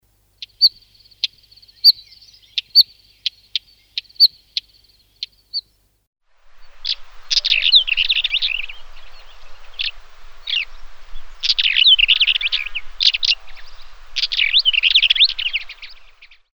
Còlit ros
Oenanthe hispanica
Oenanthe-hispanica.mp3